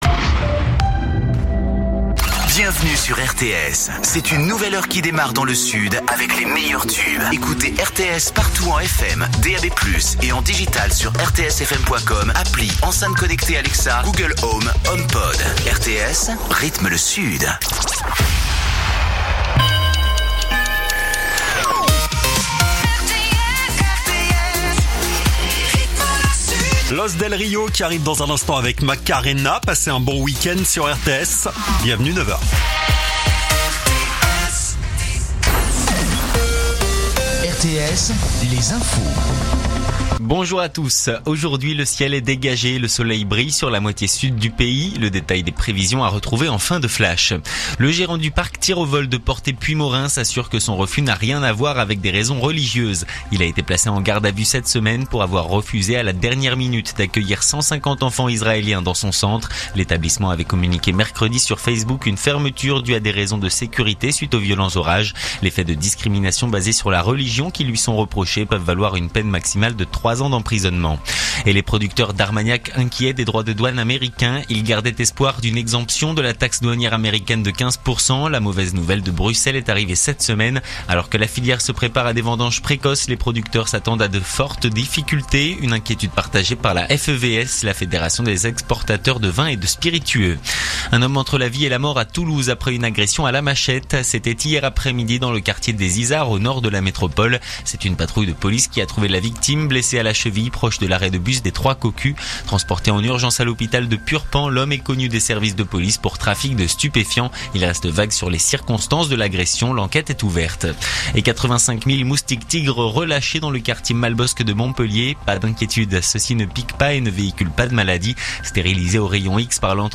info_narbonne_toulouse_484.mp3